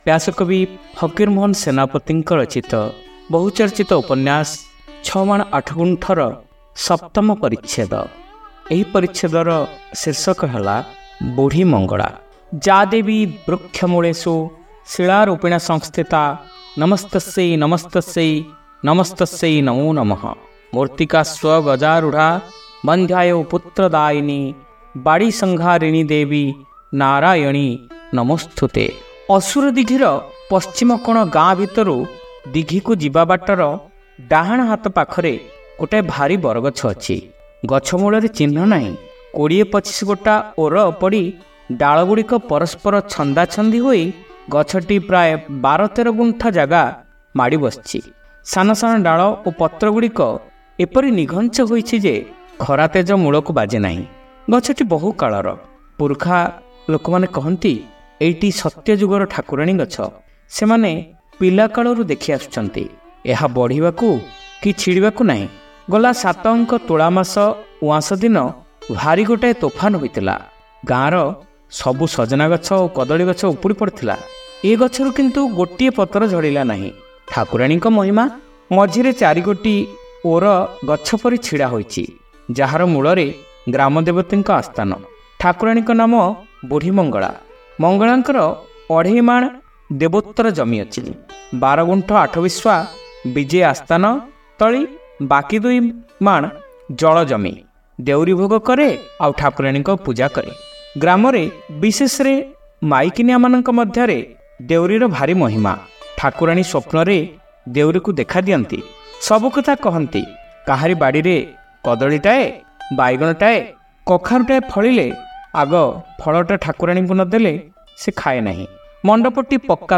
ଶ୍ରାବ୍ୟ ଉପନ୍ୟାସ : ଛମାଣ ଆଠଗୁଣ୍ଠ (ଚତୁର୍ଥ ଭାଗ)